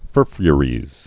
(fûrfyə-rēz)